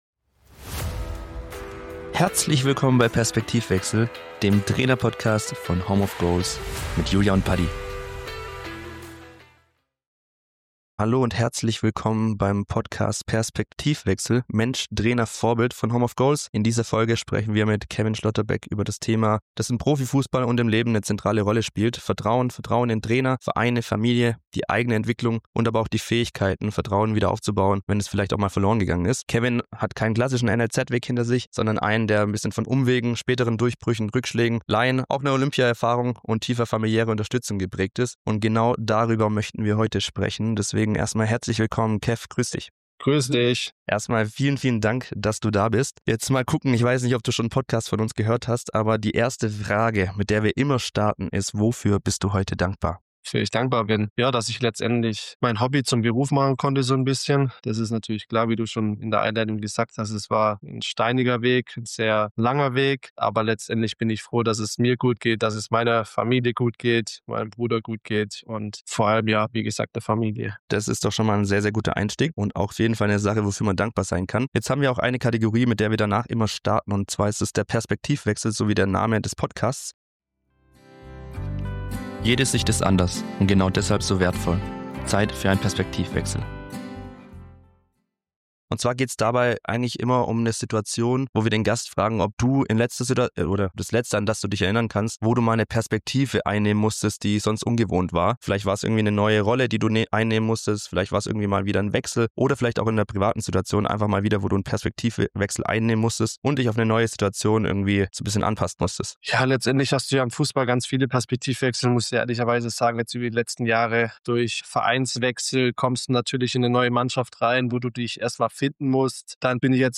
#10 Im Gespräch mit Keven Schlotterbeck ~ Perspektivwechsel Mensch. Trainer. Vorbild. Podcast